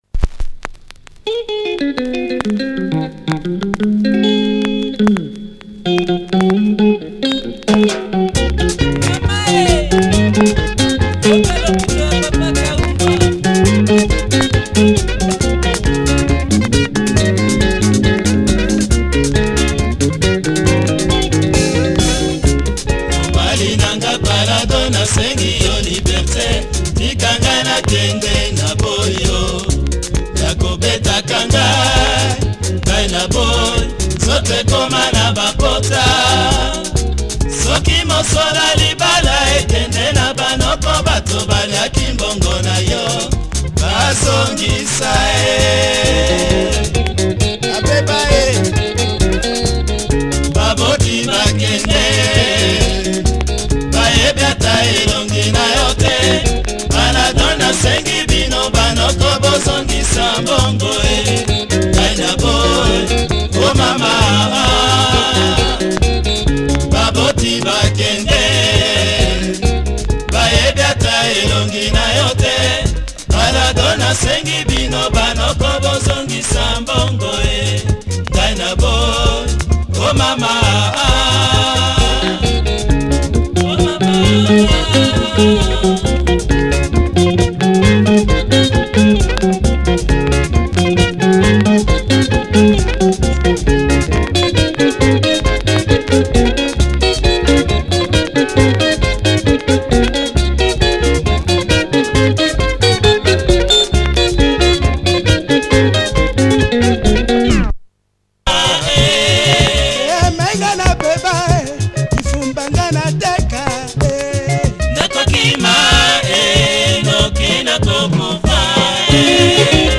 Nice Lingala track